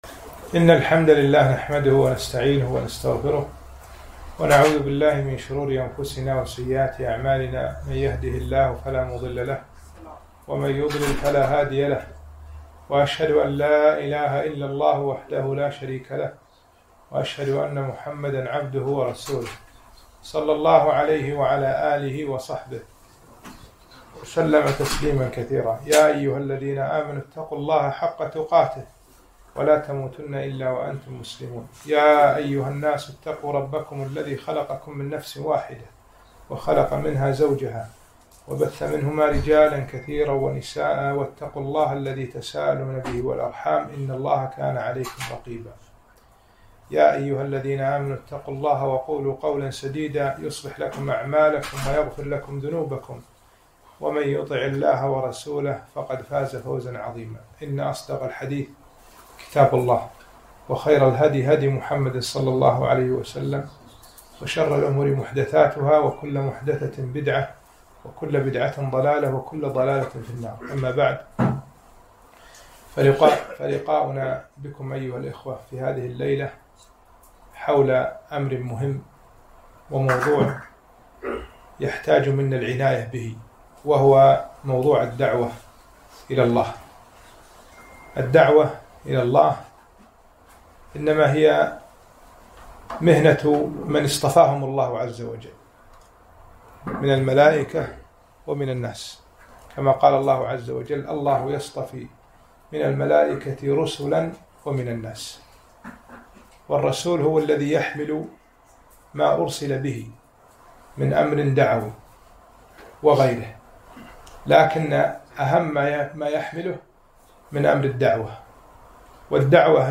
محاضرة - الدعوة إلى الله